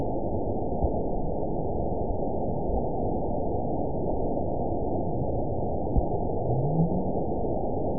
event 920380 date 03/20/24 time 21:57:39 GMT (1 year, 1 month ago) score 9.23 location TSS-AB04 detected by nrw target species NRW annotations +NRW Spectrogram: Frequency (kHz) vs. Time (s) audio not available .wav